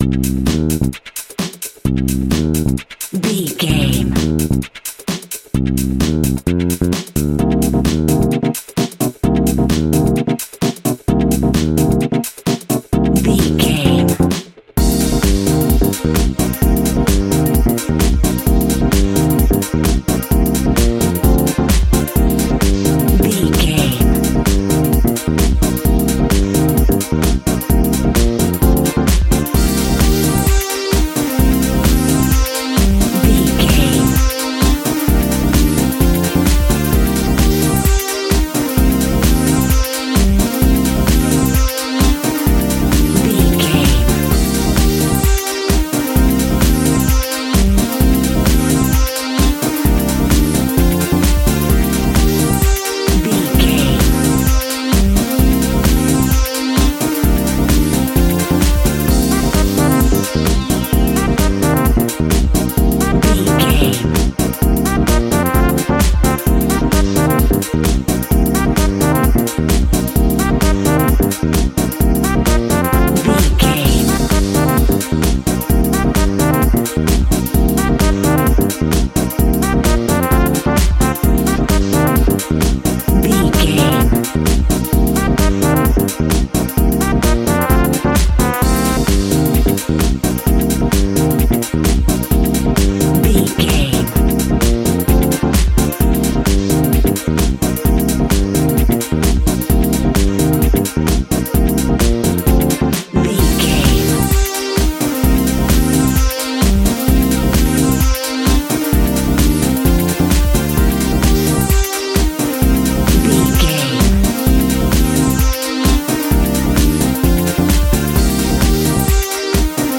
Aeolian/Minor
energetic
electric piano
synthesiser
bass guitar
drums
funky house
nu disco
upbeat
instrumentals